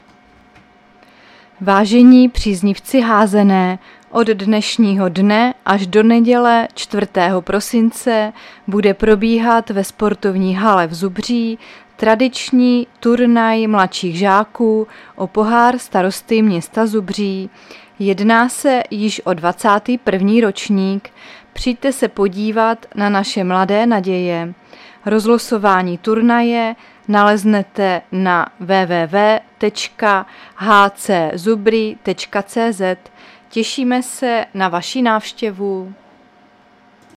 Záznam hlášení místního rozhlasu 2.12.2022